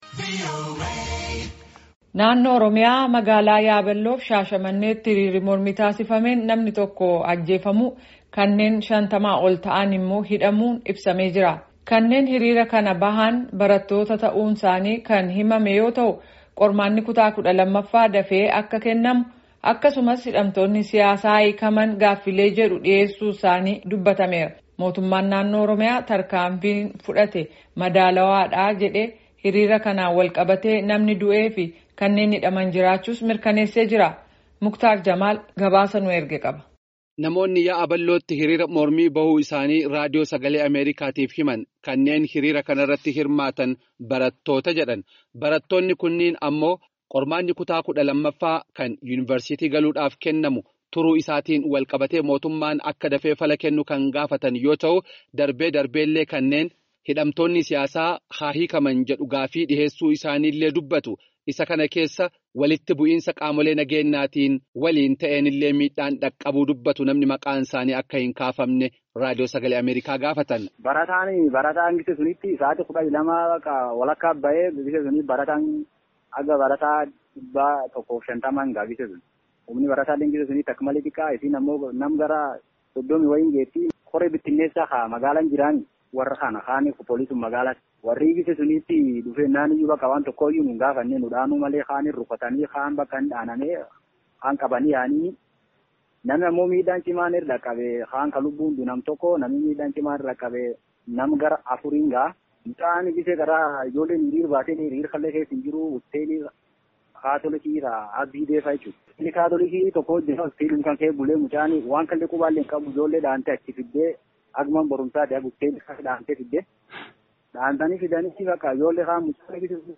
Gabaasaa guutuu caqasaa